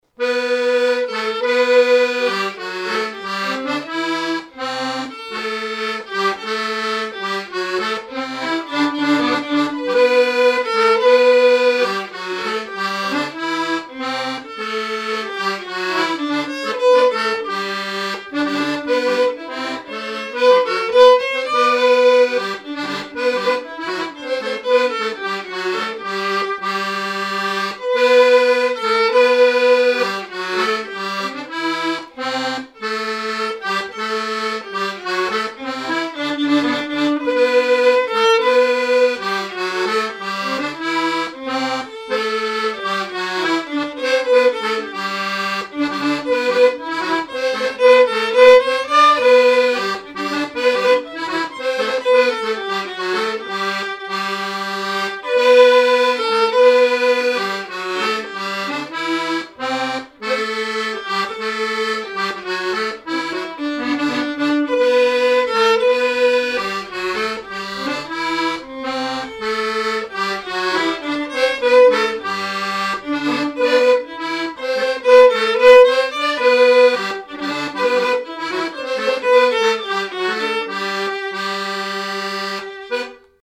Usage d'après l'informateur circonstance : fiançaille, noce
Genre brève
Pièce musicale inédite